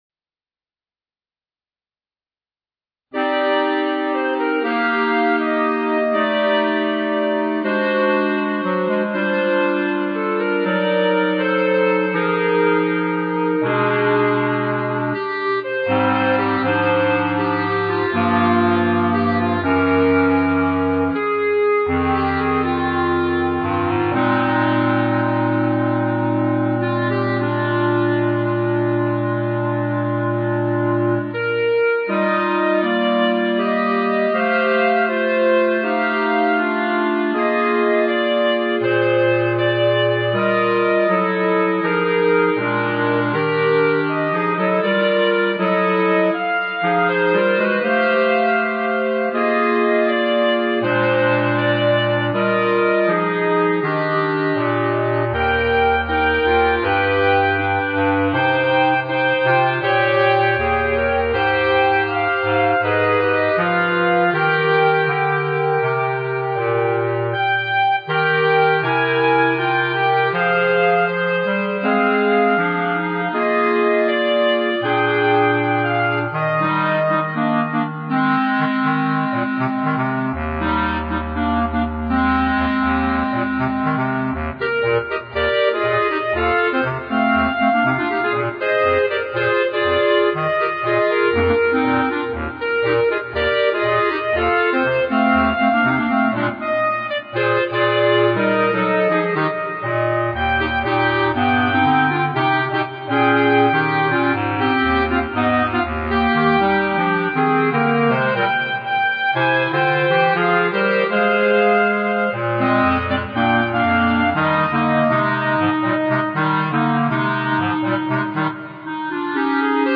B♭ Clarinet 1 B♭ Clarinet 2 B♭ Clarinet 3 Bass Clarinet
单簧管四重奏
摇滚 , 民谣
请您在演奏时，感受那温柔而沁人心脾的音响。